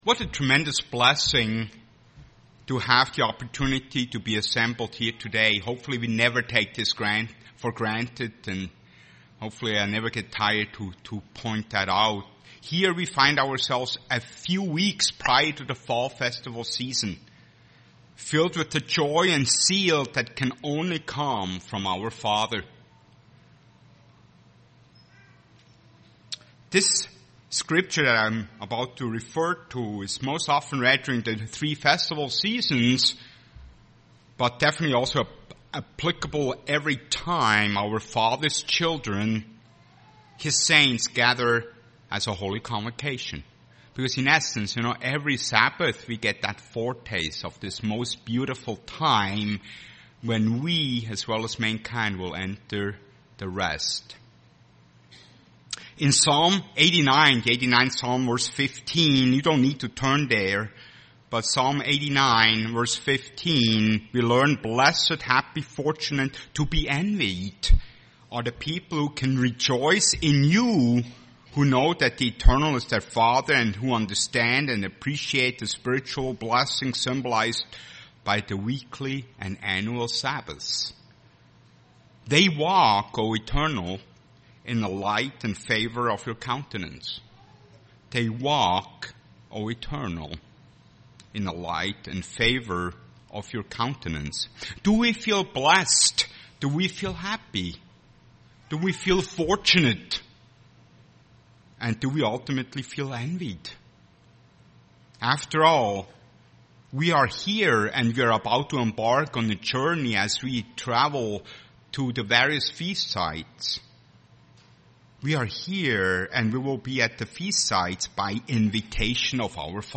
Given in Twin Cities, MN
UCG Sermon relationships Studying the bible?